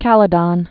(kălĭ-dŏn, -dən)